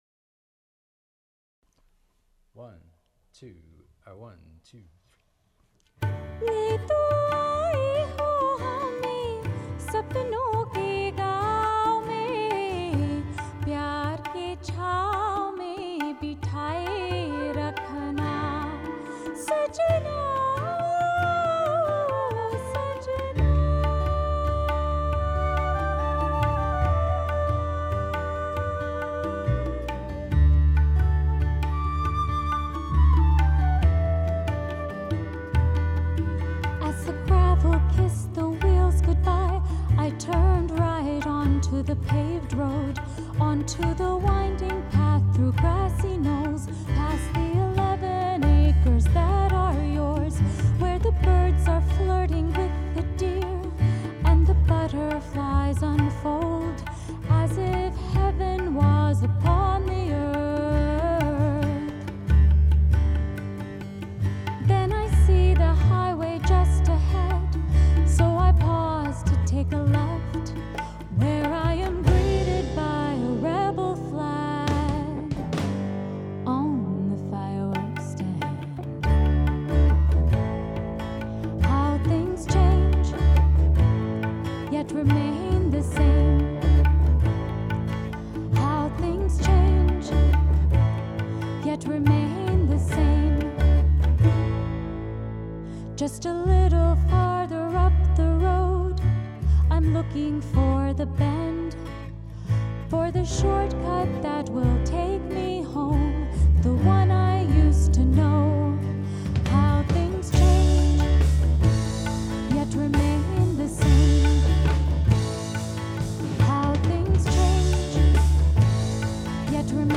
ROUGH MIX